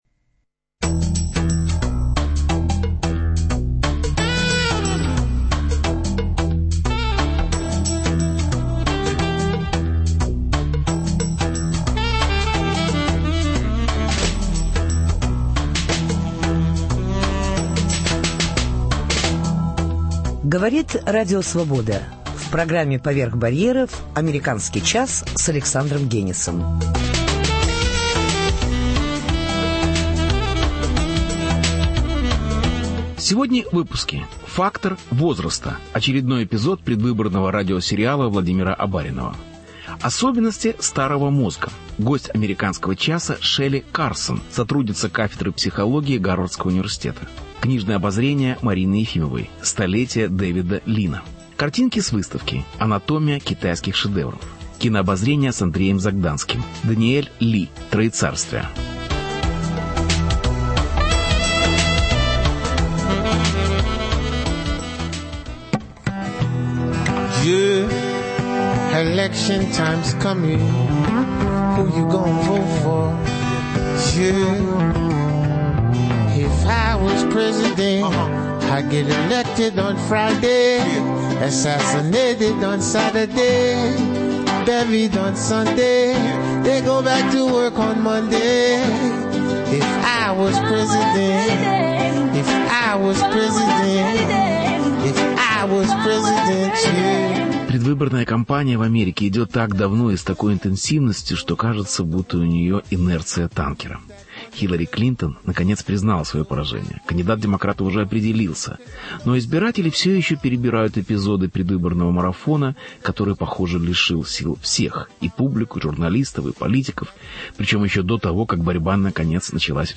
Репортаж. Фактор возраста.